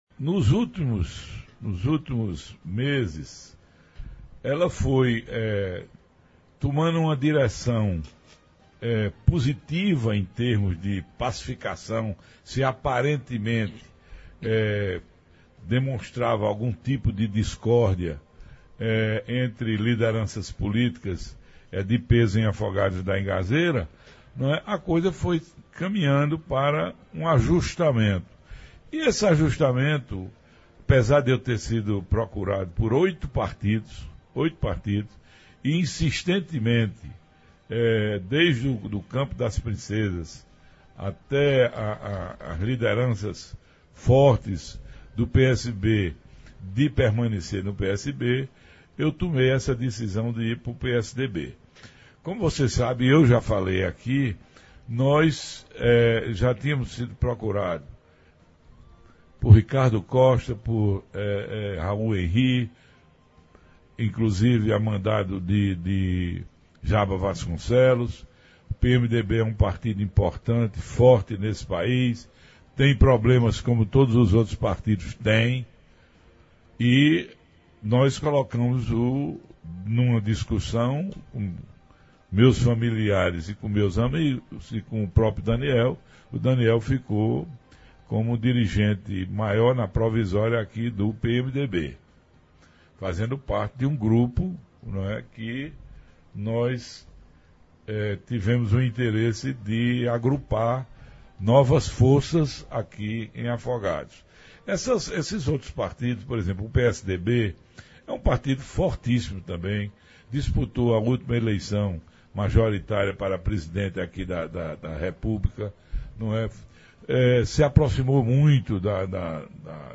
O debate teve ainda perguntas dos blogueiros